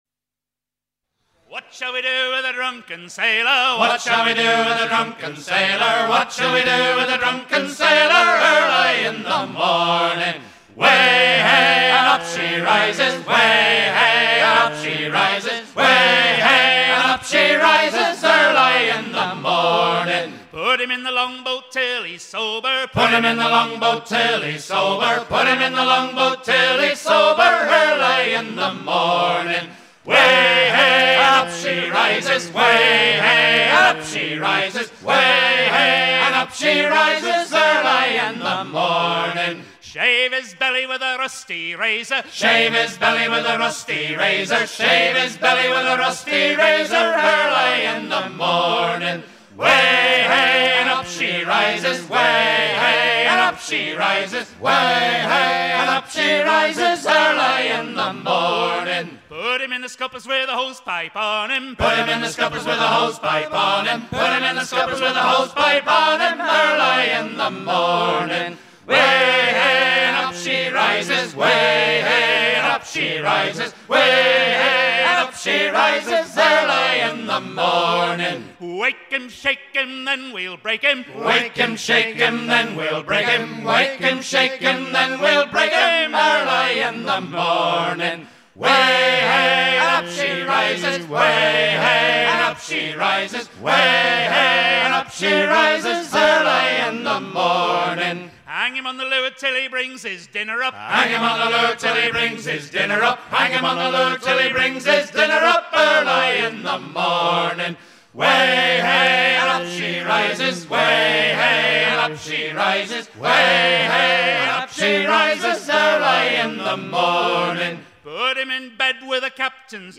Chants des marins anglais